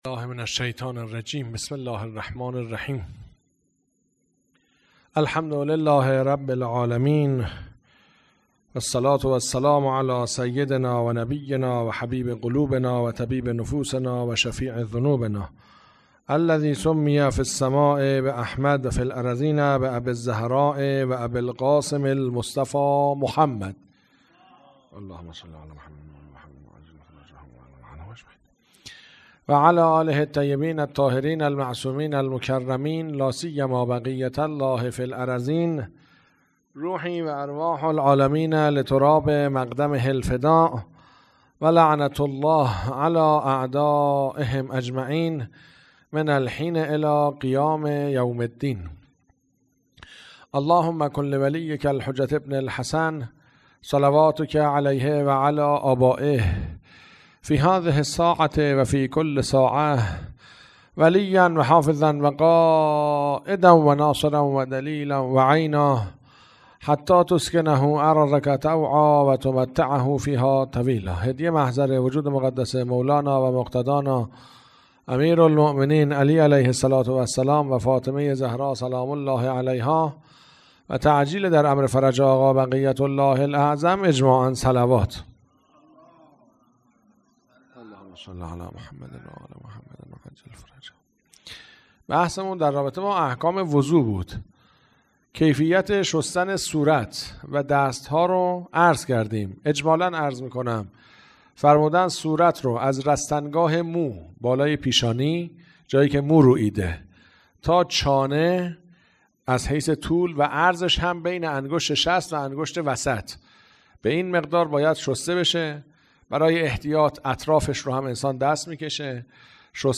هیئت عقیله بنی هاشم سبزوار